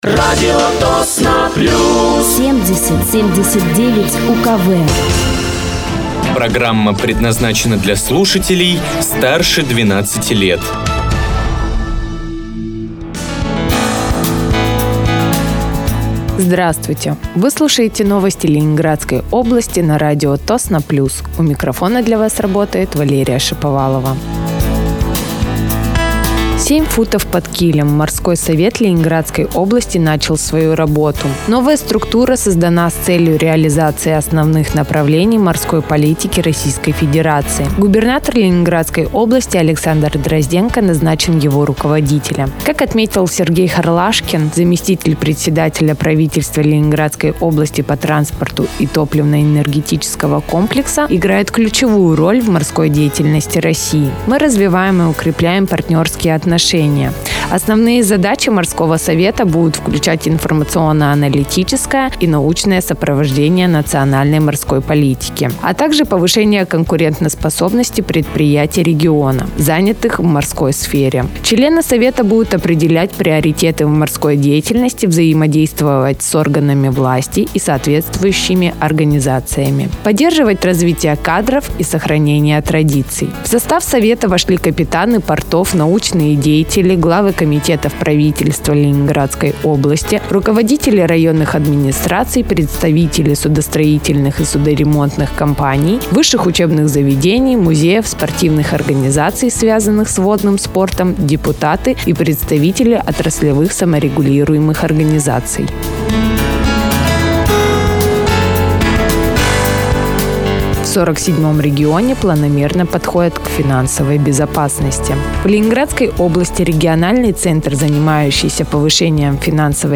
Вы слушаете новости Ленинградской области на радиоканале «Радио Тосно плюс».